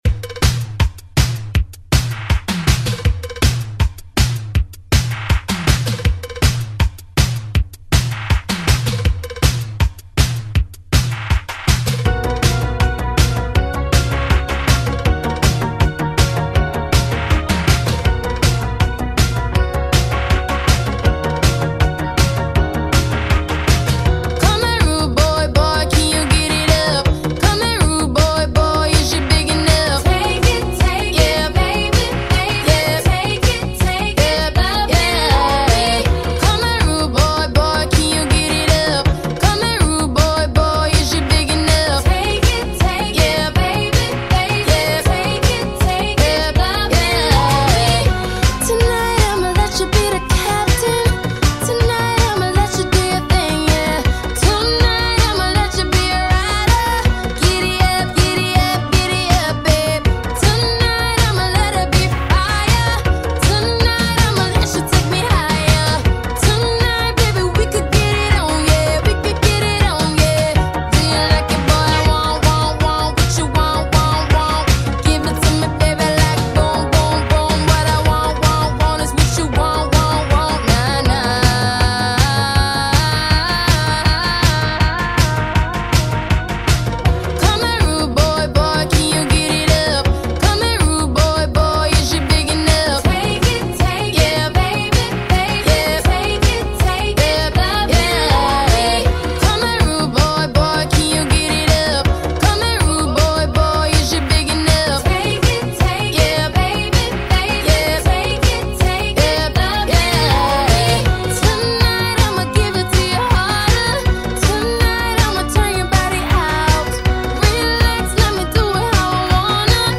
¡un A+B que no defraudará a la pista de baile!